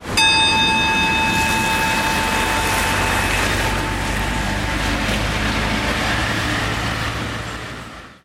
Einleitung der Schweigeminute durch die Klangschale (Audio 2/2) [MP3]